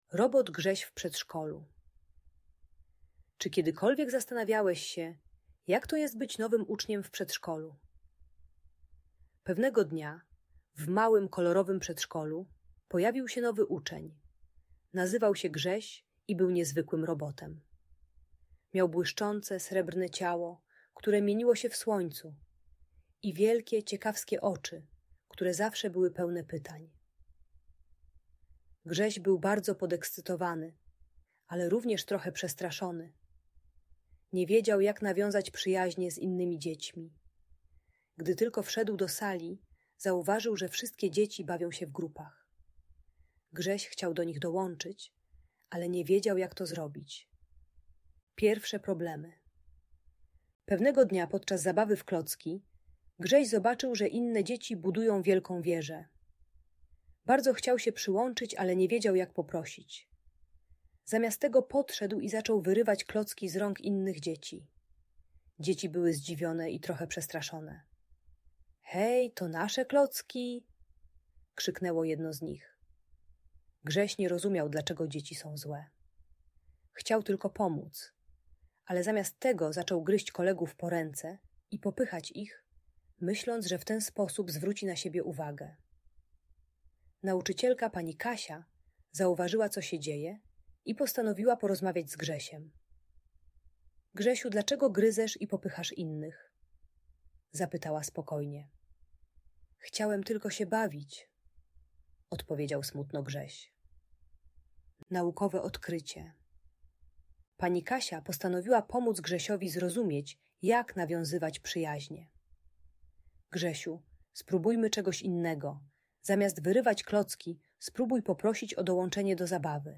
Robot Grześ w Przedszkolu - Urocza Opowieść o Przyjaźni - Audiobajka dla dzieci